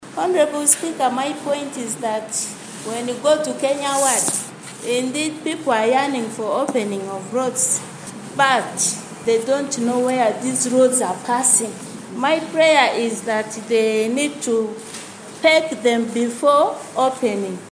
Molly Ondoru, a woman counselor representing Kenya Ward, echoed the sentiment of community sensitization. She emphasized the necessity of educating the local population about the forthcoming road opening initiatives.